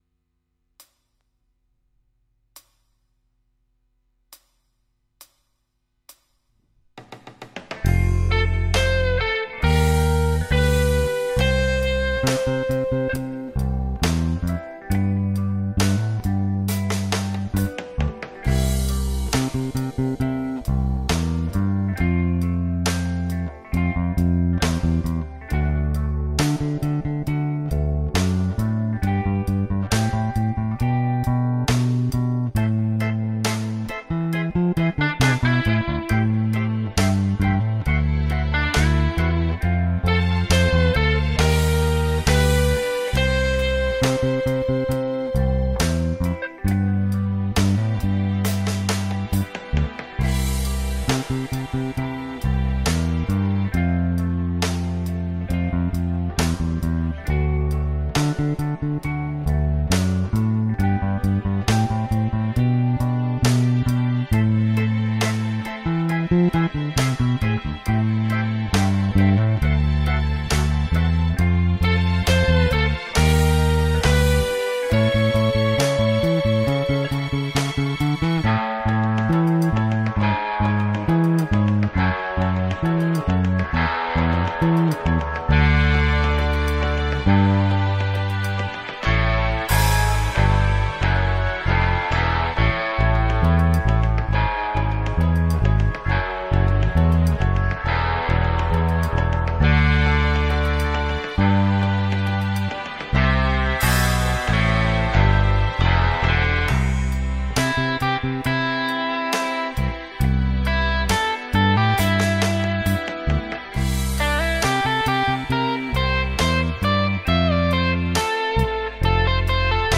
Neck Pickup